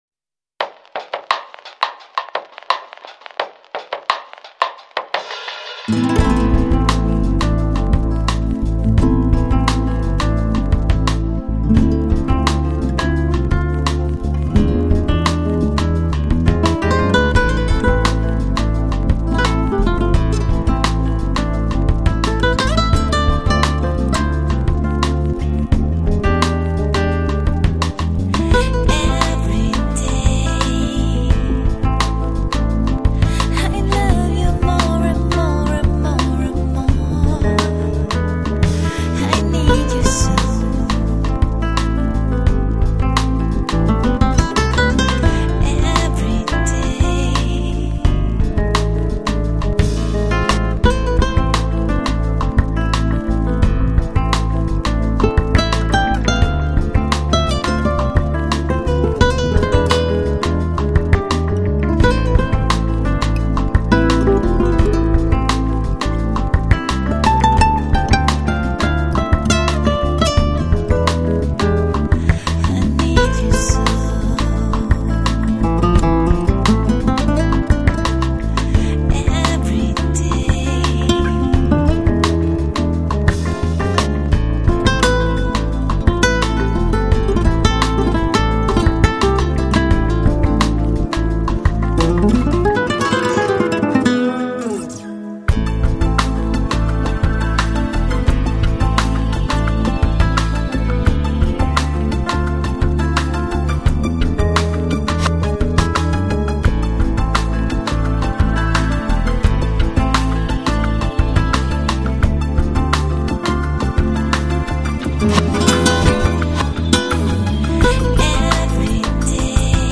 Жанр: Electronic, Lounge, Chill Out, Downtempo, Flamenco